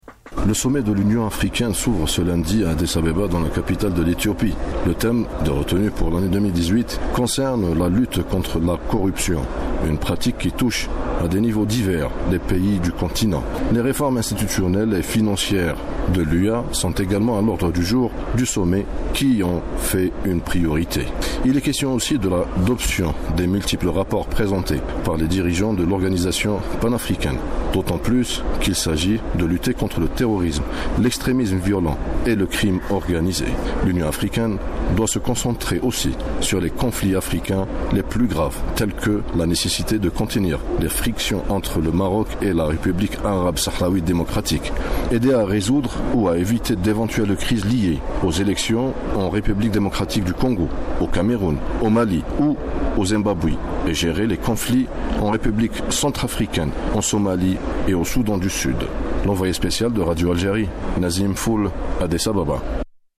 Intervention du premier-ministre, Ahmed Ouyahia, à propos du NEPAD